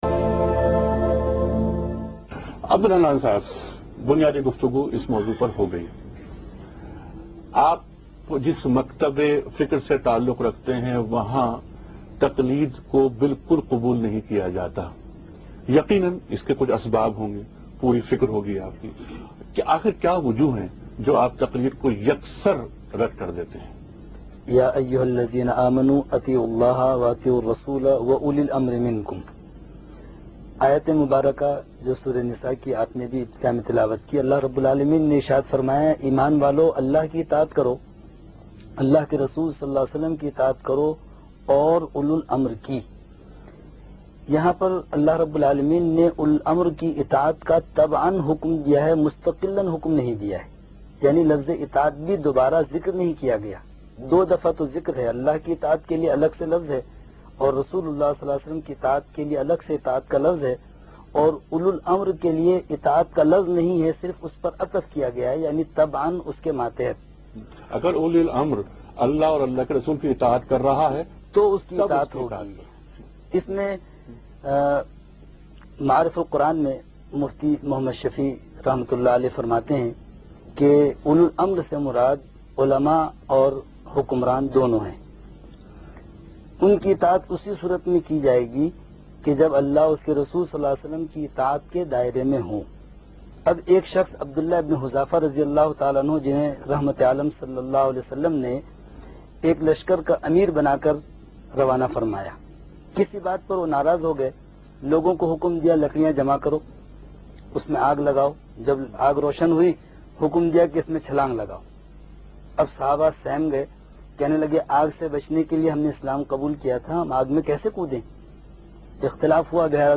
Category: TV Programs / Geo Tv / Alif /